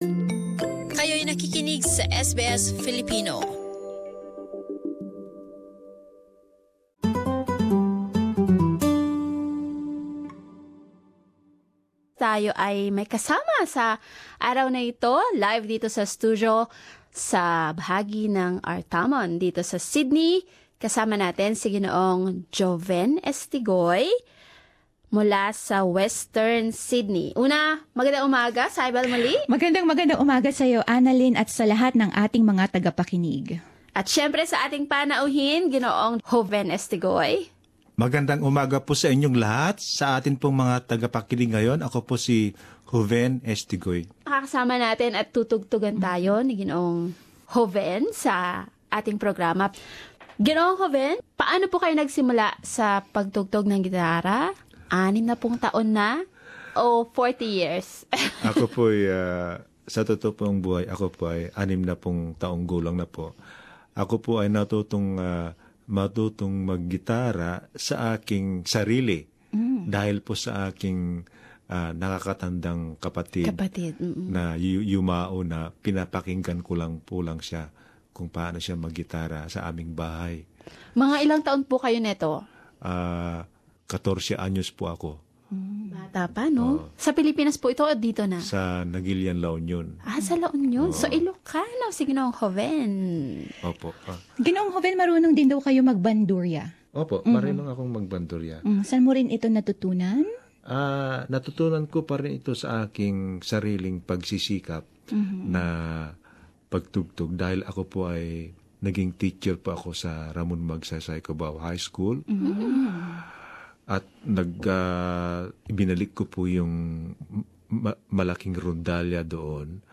He shares with us his love of classical music and plays the guitar and bandurria.